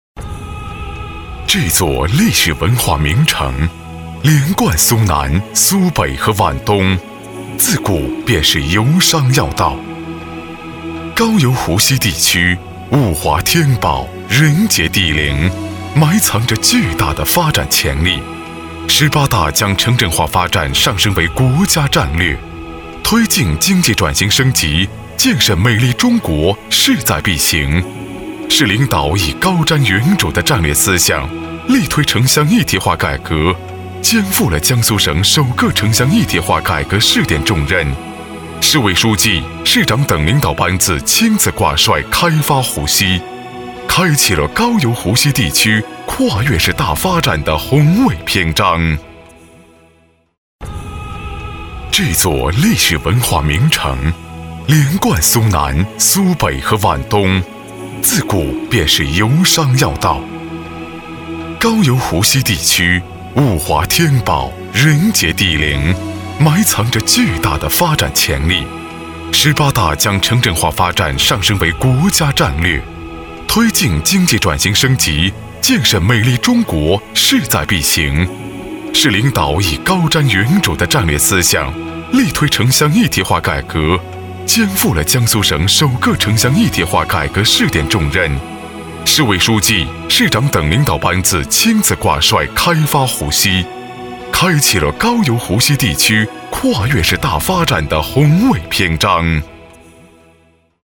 国语青年大气浑厚磁性 、沉稳 、科技感 、男专题片 、宣传片 、200元/分钟男S355 国语 男声 专题片-大渡口镇-政府专题-深沉浑厚 大气浑厚磁性|沉稳|科技感